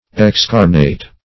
Search Result for " excarnate" : The Collaborative International Dictionary of English v.0.48: Excarnate \Ex*car"nate\, v. t. [LL. excarnatus, p. p. of excarnare; L. ex out + caro, carnis, flesh.]